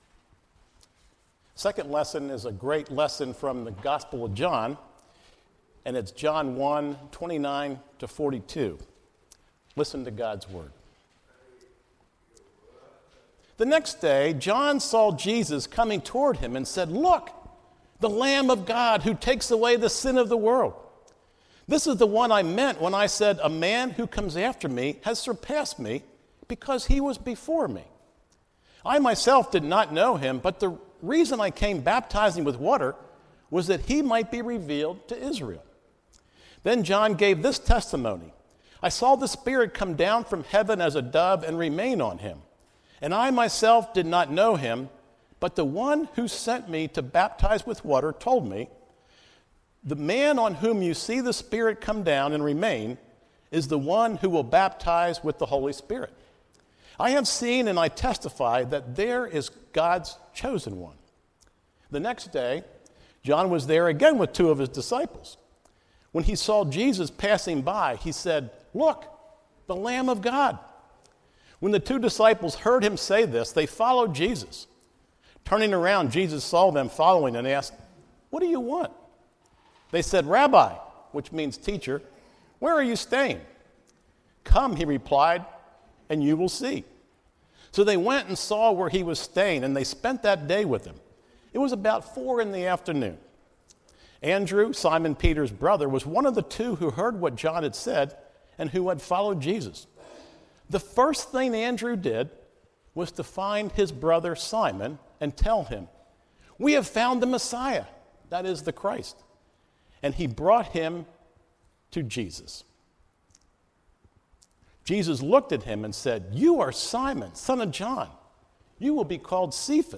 Guest Pastor